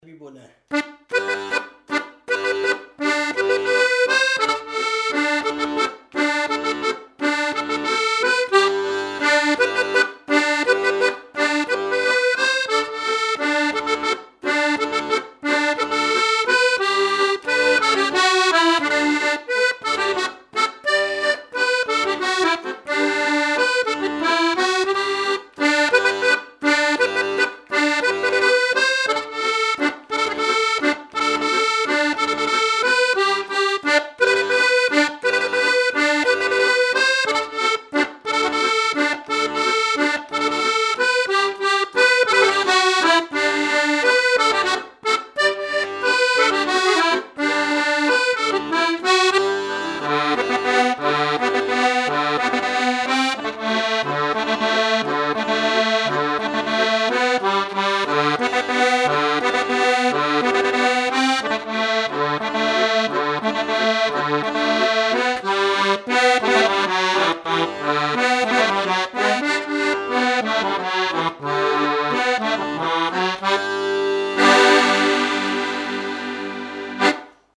Boulogne
Chants brefs - A danser
danse : scottich trois pas
Pièce musicale inédite